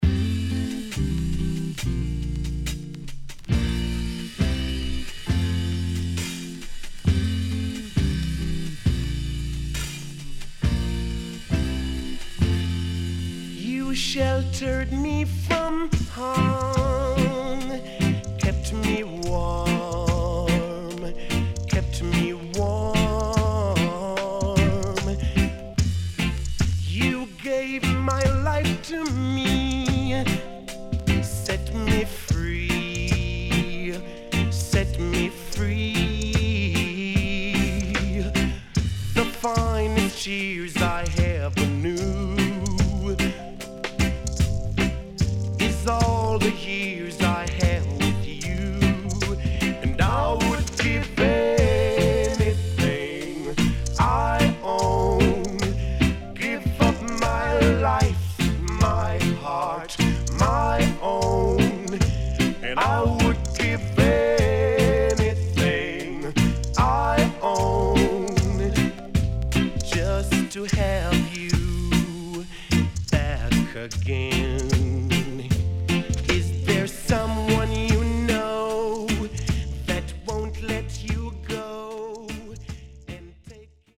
HOME > LP [VINTAGE]  >  定番70’s
SIDE A:少しチリノイズ入りますが良好です。